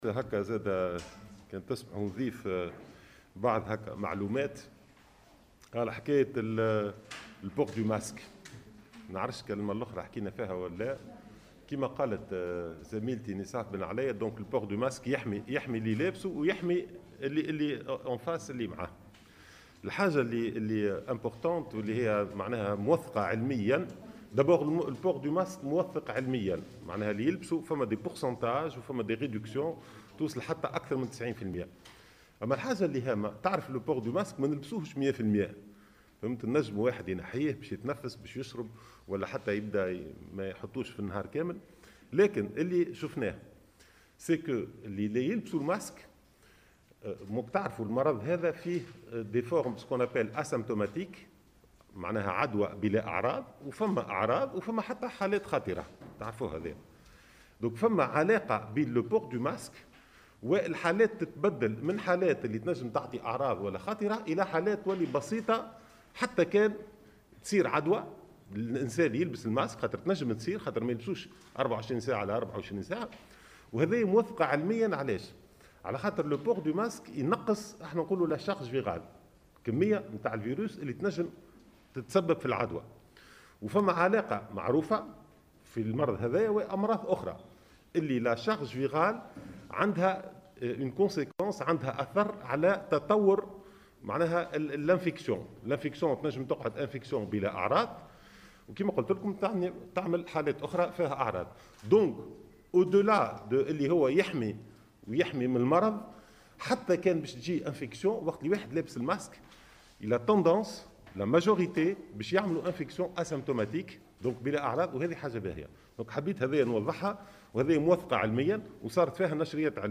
علّق المدير العام لمعهد باستور في تونس الهاشمي الوزير في رده على امكانية اعلان حظر التجوّل بالقول (بالدارجة التونسية): "ماناش في حرب توّا".
وجاء ذلك في سياق حديثه عن الاجراءات المحتملة والتي قد يتم اتخاذها لمقاومة تفشي وباء كورونا خلال ندوة صحفية عقدتها اليوم وزارة الصحة شدد خلالها في المقابل على ضرورة الالتزام بالاجراءات الوقائية وأساسا غسل اليدين والتباعد الجسدي وارتداء "الكمامة".